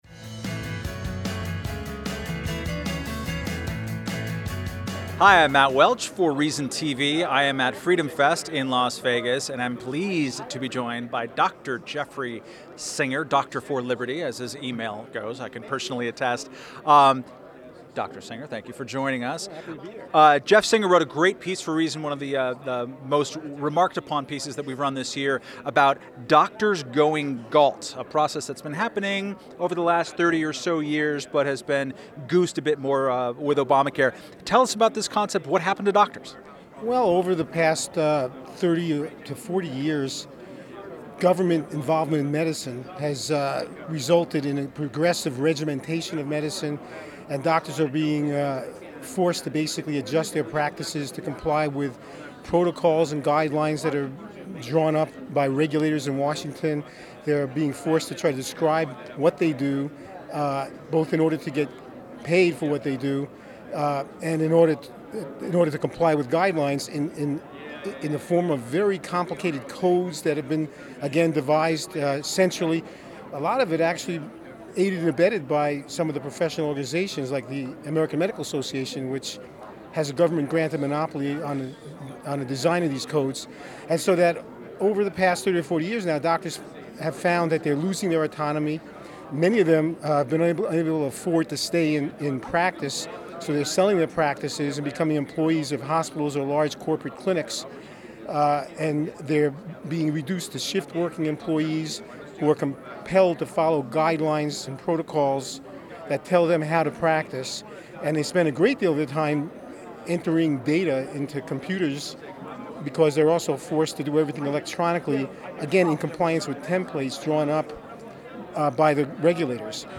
Q&A
Held each July in Las Vegas, FreedomFest is attended by around 2,000 limited-government enthusiasts and libertarians.